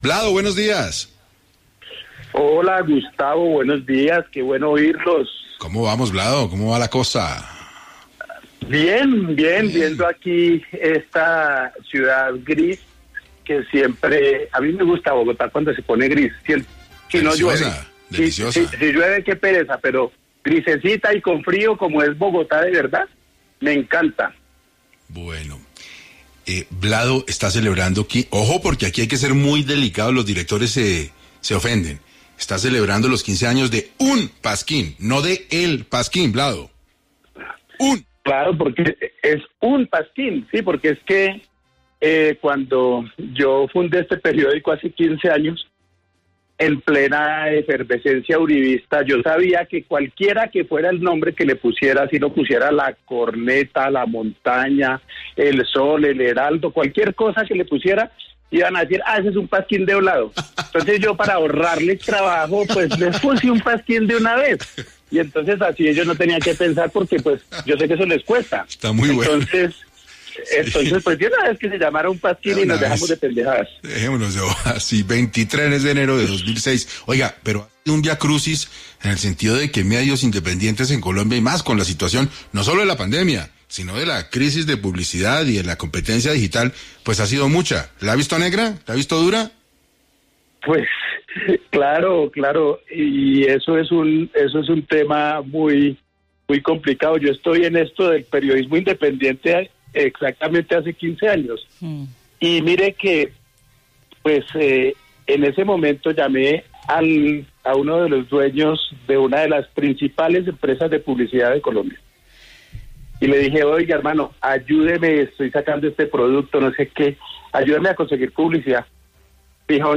El egresado del programa de Publicidad de Utadeo, Vladimir Flórez, habló en Hoy por Hoy de Caracol Radio sobre los retos que ha tenido desde que fundó este medio independiente.
Durante la entrevista, el tadeísta habló sobre la crisis que los medios independientes viven sobre la pandemia y de la dificultad que experimentan a la hora de vender pauta. También, en su estilo particularmente jocoso, señaló que el secreto de su medio es que “es el único periódico verdaderamente gratuito del país: los colaboradores escriben gratis y los lectores leen gratis”.